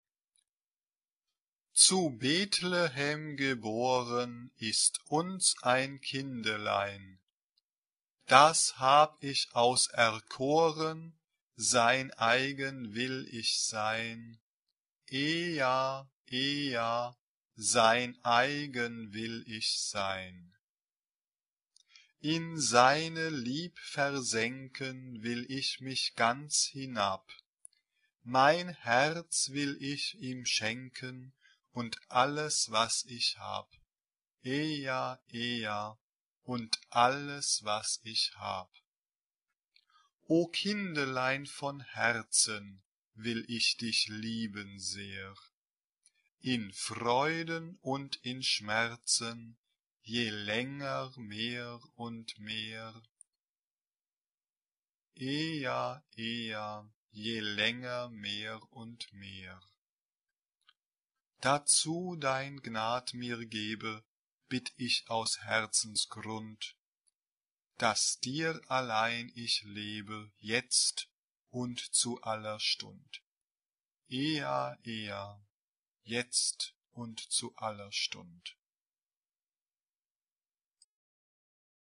SATB (4 voix mixtes) ; Partition complète.
Sacré ; noël Type de choeur : SATB (4 voix mixtes )
Tonalité : la bémol majeur